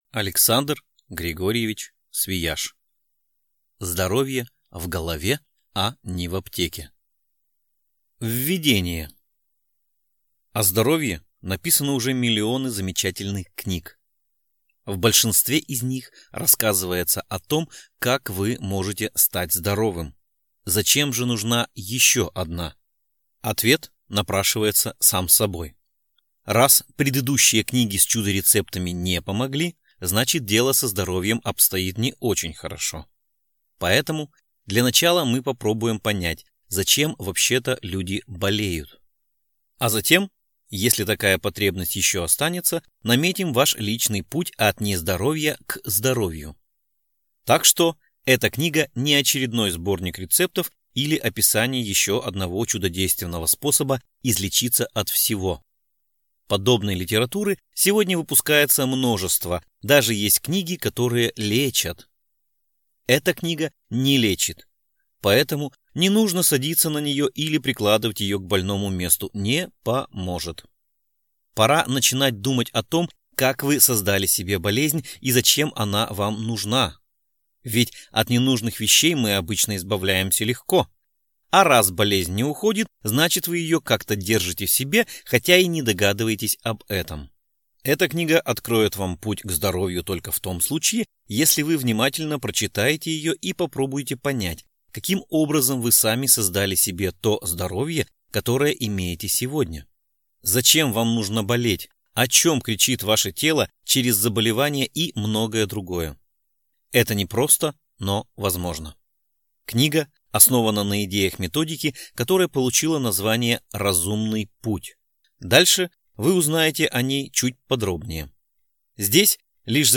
Аудиокнига Здоровье в голове, а не в аптеке | Библиотека аудиокниг